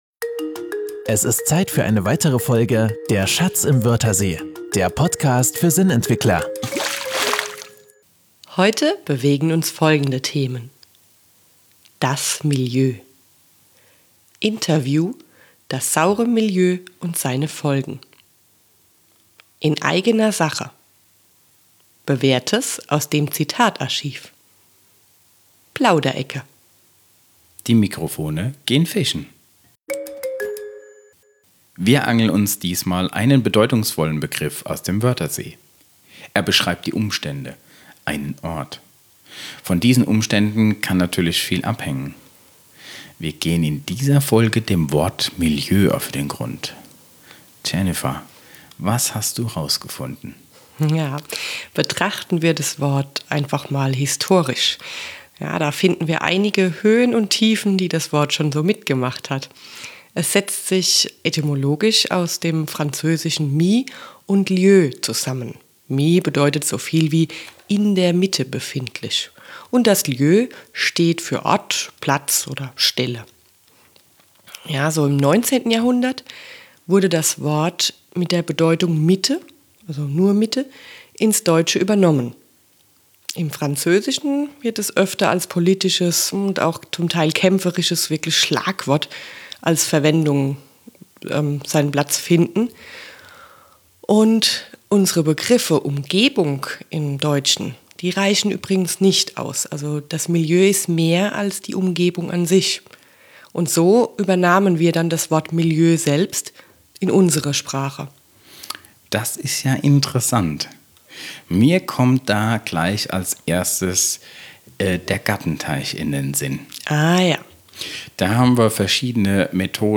Zwei Angler auf der Suche nach dem passenden Begriff.